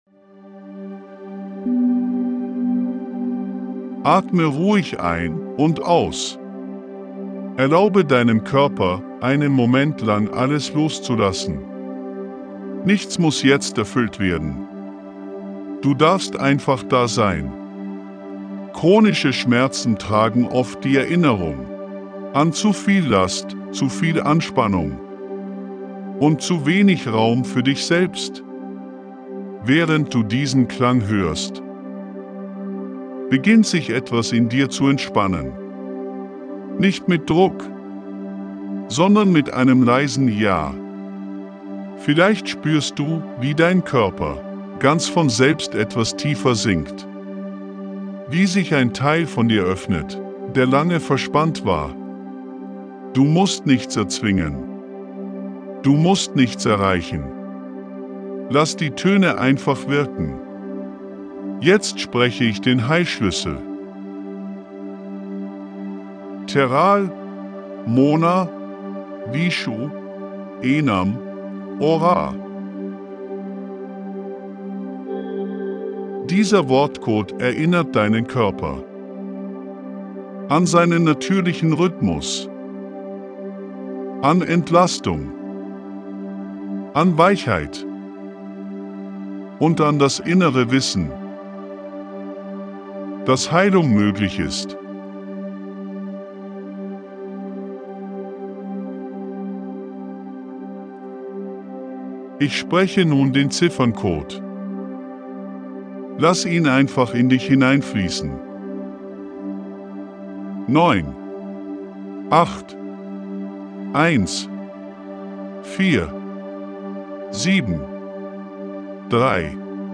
🎧 Die Audio-Meditation zum